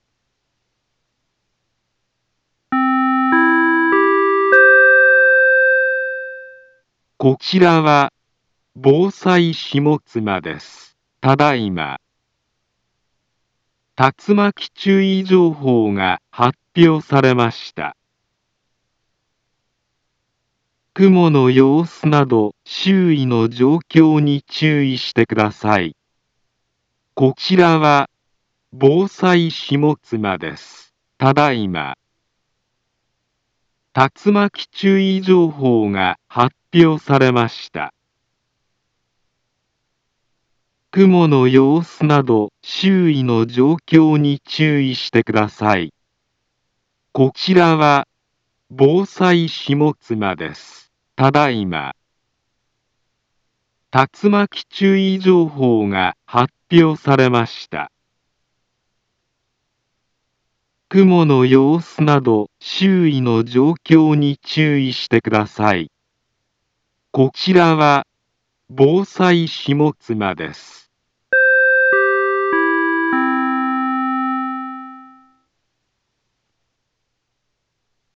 Back Home Ｊアラート情報 音声放送 再生 災害情報 カテゴリ：J-ALERT 登録日時：2023-07-13 21:45:03 インフォメーション：茨城県北部、南部は、竜巻などの激しい突風が発生しやすい気象状況になっています。